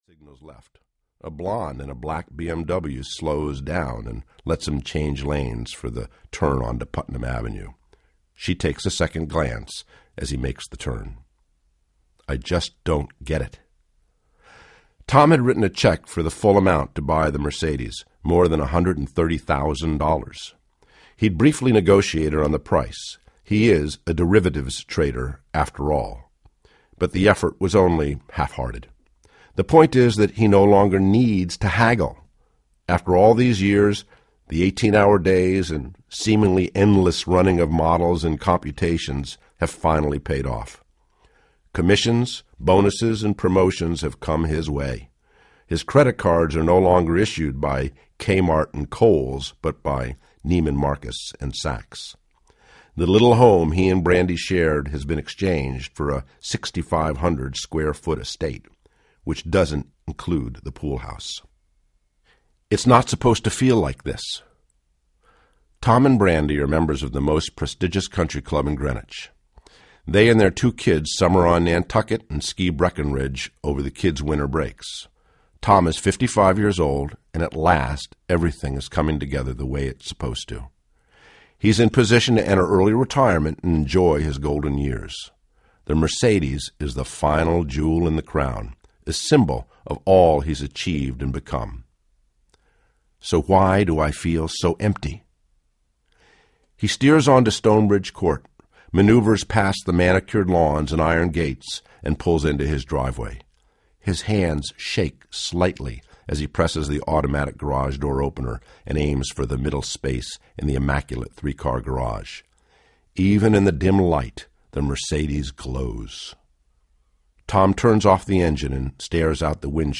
New Man Journey Audiobook
Narrator
6.75 Hrs. – Unabridged